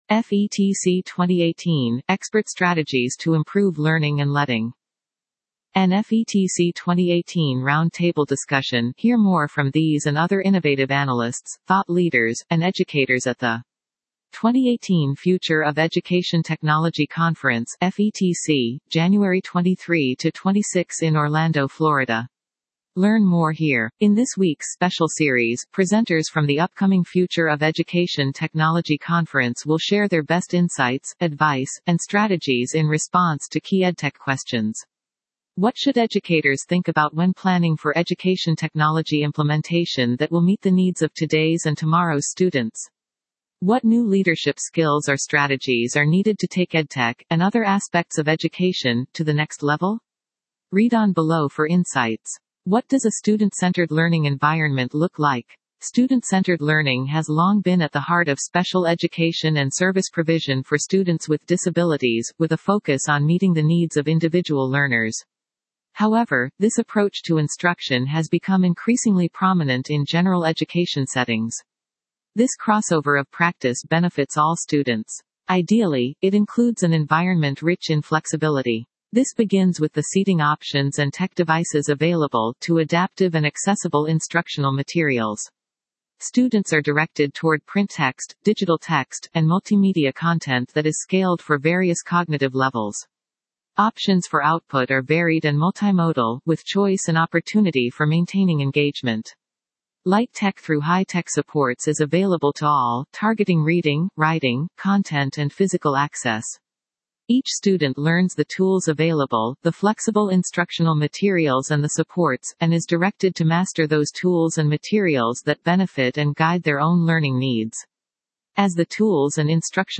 A Round Table Discussion about expert strategies to improve learning and leading in the classroom with innovative analysts, thought leaders, and educators presenting and attending the 2018 Future of Education Technology Conference (FETC), January 23-26 in Orlando, Florida.